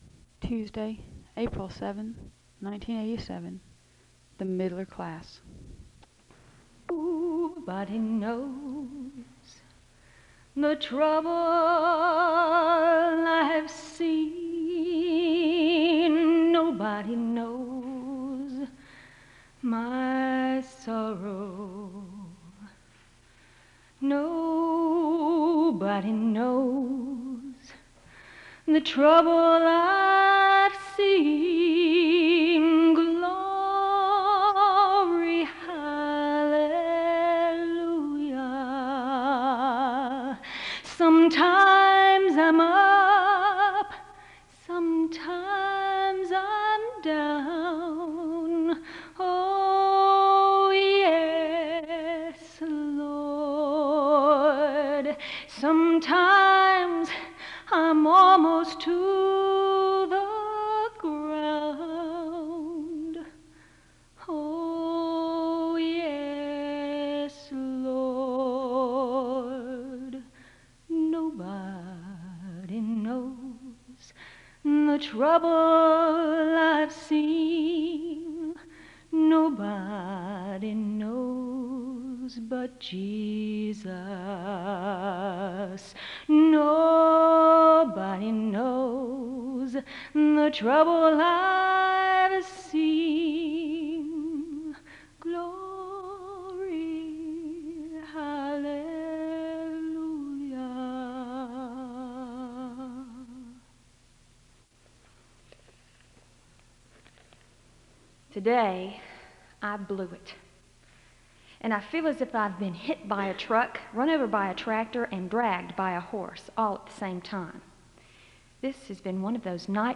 Download .mp3 Description This chapel service is facilitated by the Middler class at Southeastern Baptist Theological Seminary. The service begins with a song of worship (0:00-1:44). There is a story shared about not the struggles of life (1:45-6:12).
A woman sings a song of worship (15:15-17:31).
There is a group Scripture reading (29:59-32:50).